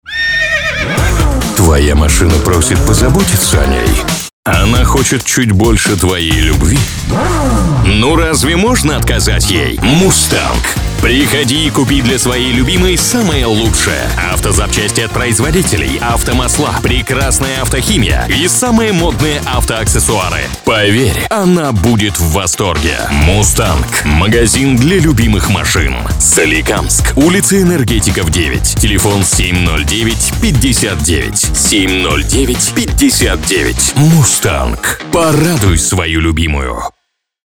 Текст ролика для радио (Автозапчасти и аксессуары)